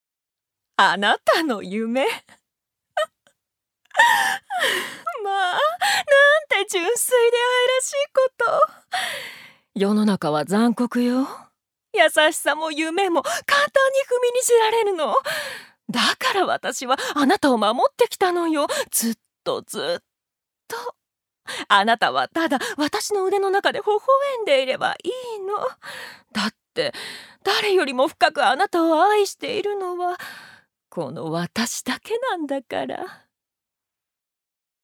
ジュニア：女性
セリフ３